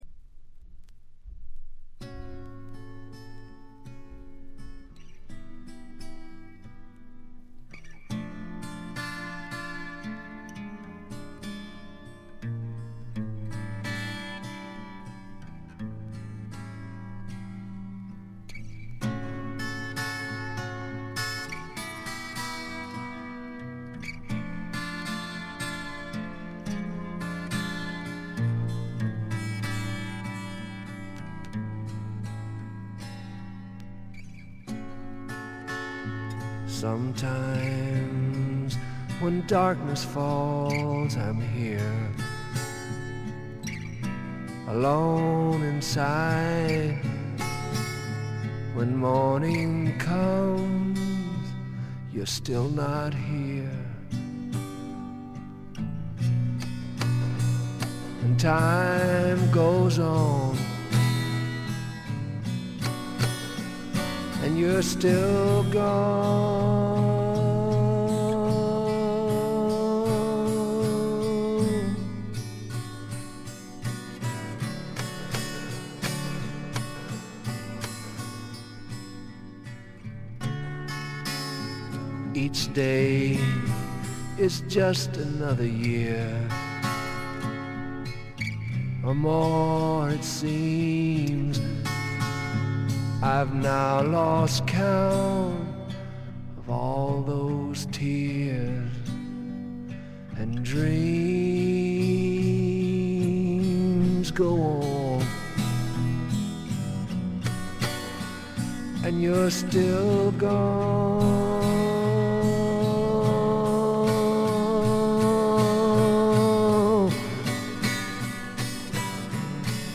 試聴曲は現品からの取り込み音源です。
※エンディングでプツ音が出ますが、洗浄で取り切れなかった微細なゴミが食いついていたためで、その後除去して音にも出ません。